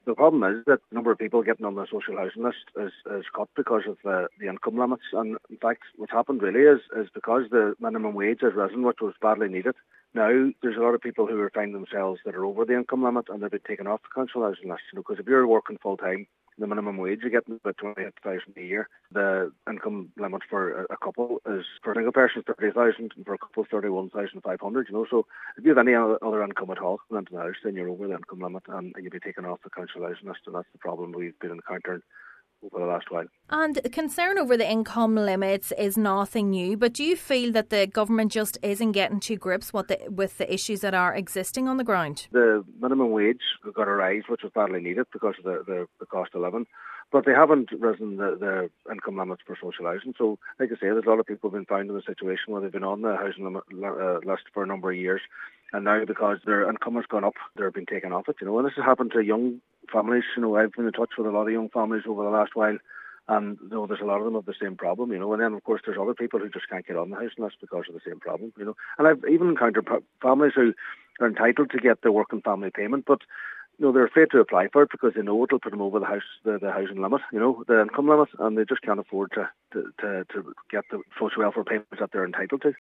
Councillor Brogan says a joint-up approach is needed: